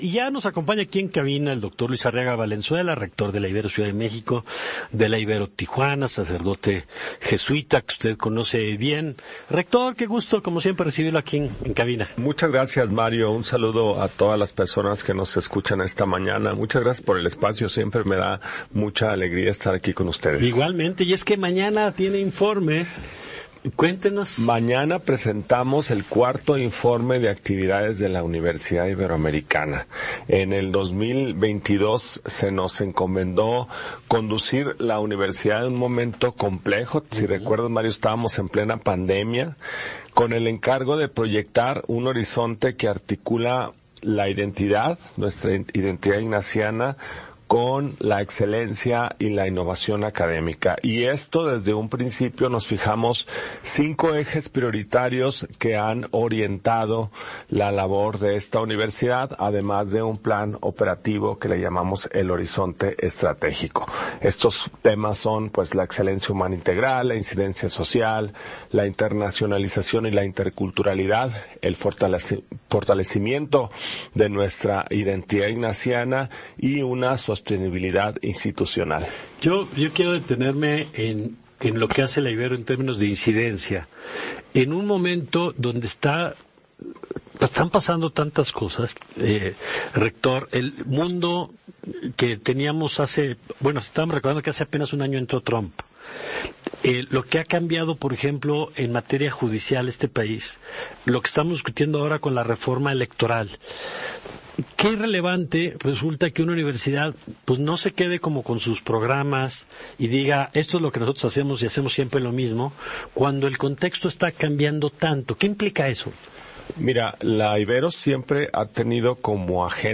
en entrevista para Ibero 90.9